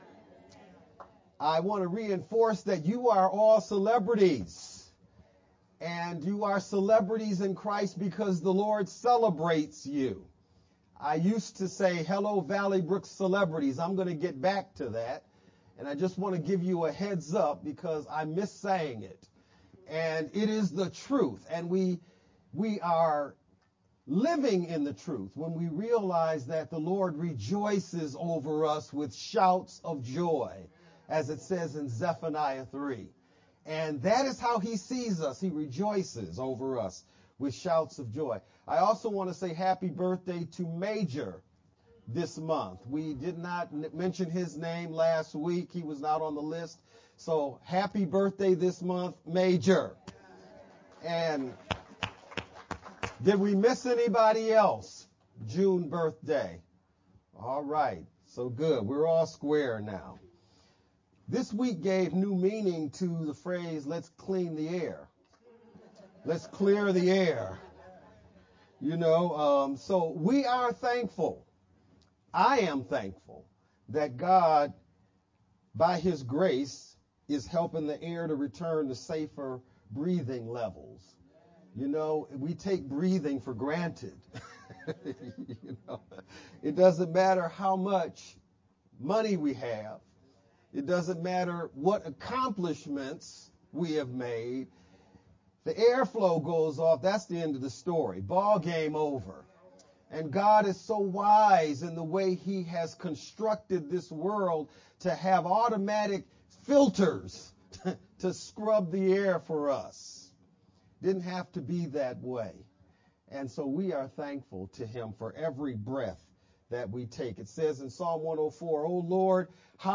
June-11th-Sermon-only-Mp3_Converted-CD.mp3